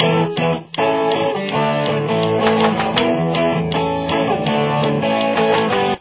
Here it is looped at normal speed of about 160 bpm.:
Intro Riff